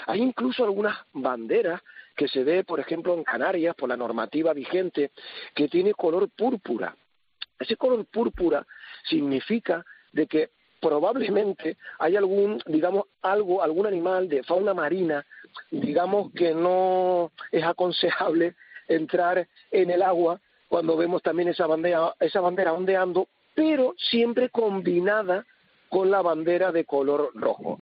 Un técnico de la Cruz Roja explica el significado de la bandera púrpura